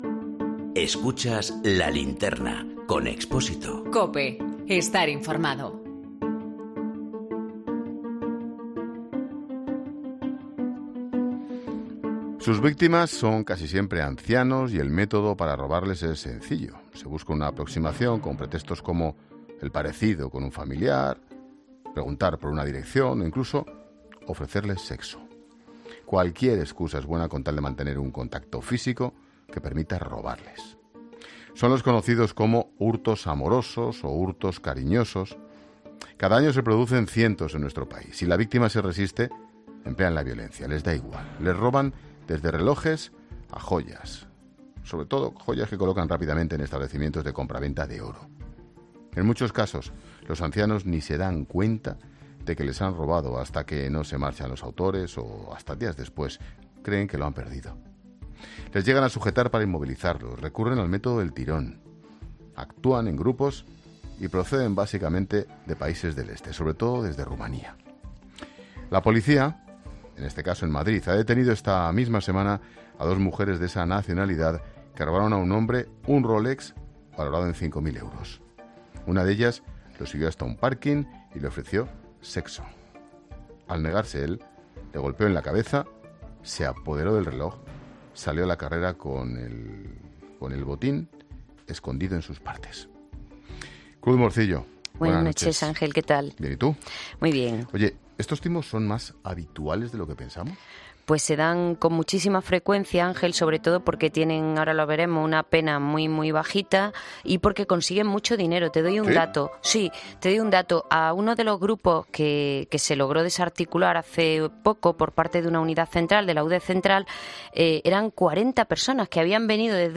ha pasado por los micrófonos de 'La Linterna' para hablar de estos hurtos amorosos.